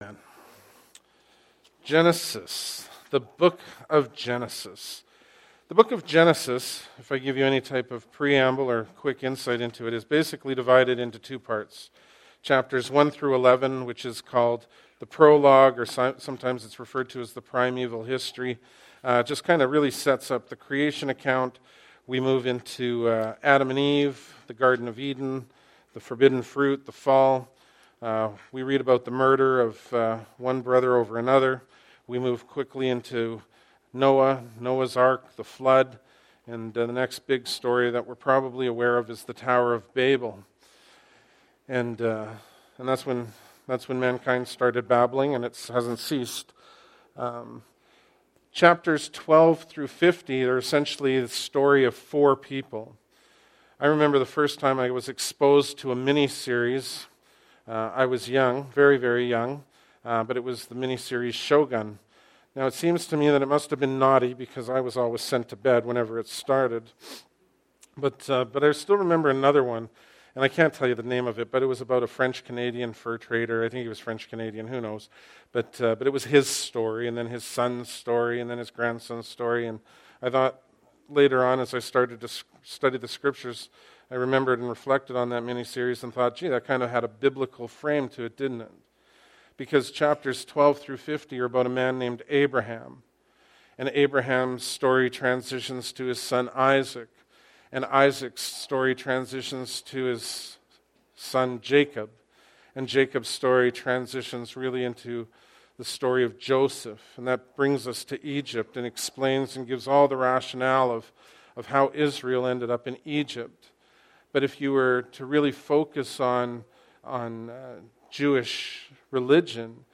Bible Text: Genesis 1 | Preacher: